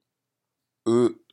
japanese_u_vowel.m4a